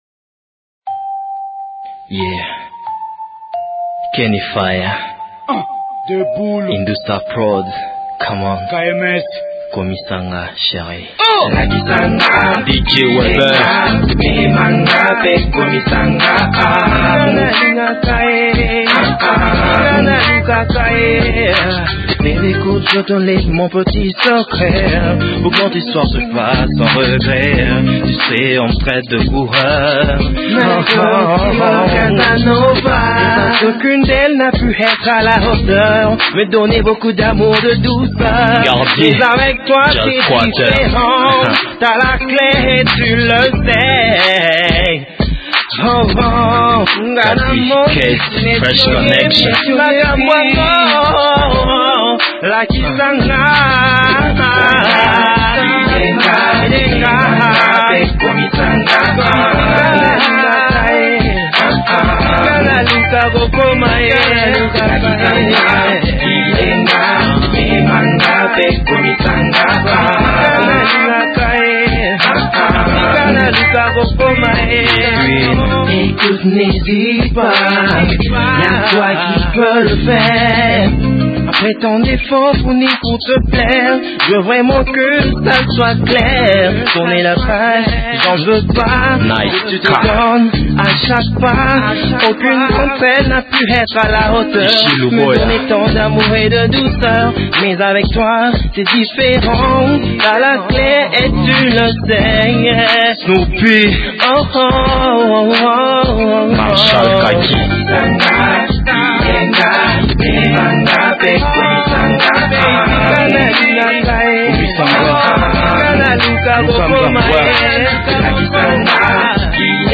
une musique caractérisée par la vitalité et le dynamisme